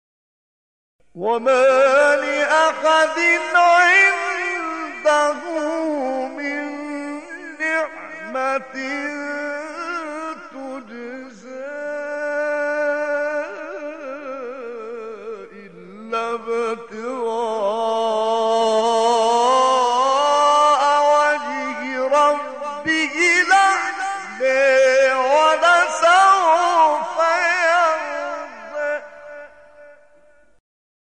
گروه فعالیت‌های قرآنی: مقاطع صوتی دلنشین از قراء بین‌المللی جهان اسلام را می‌شنوید.